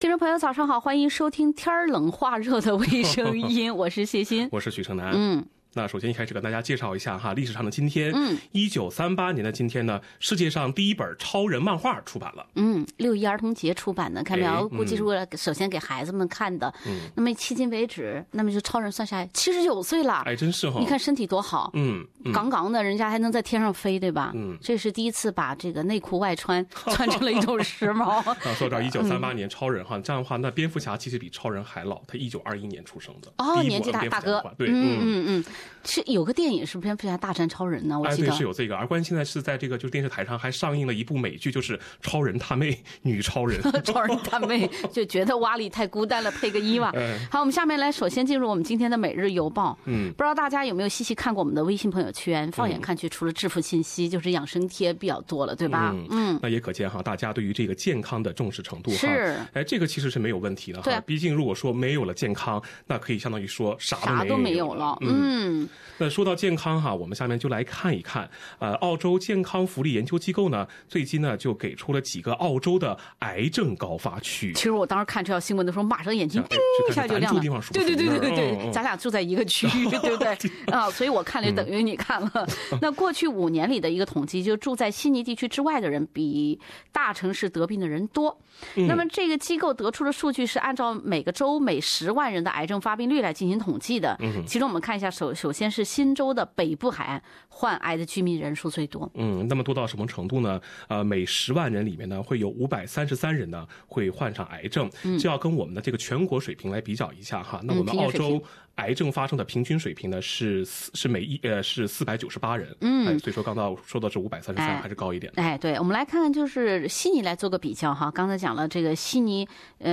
另类轻松的播报方式，深入浅出的辛辣点评；包罗万象的最新资讯；倾听全球微声音。